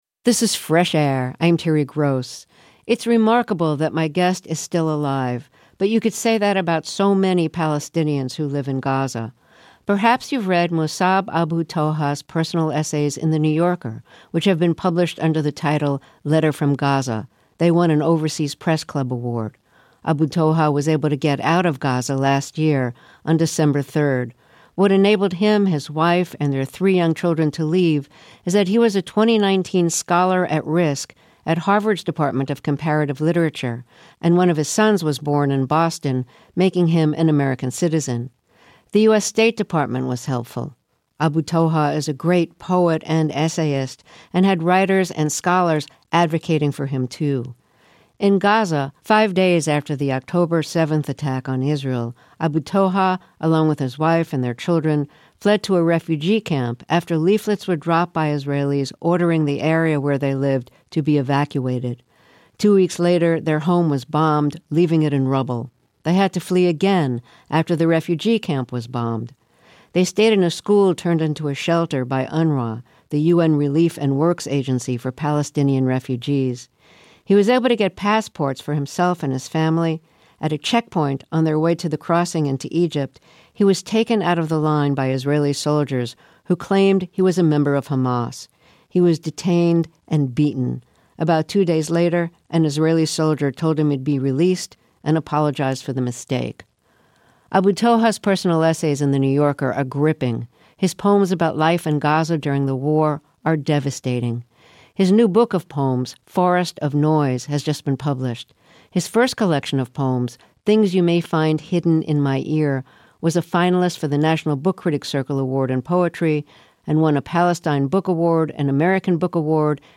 Mosab Abu Toha was able to escape Gaza, along with his wife and three young children. The award-winning poet talks about parenting in war and the devastation of leaving his family and friends behind.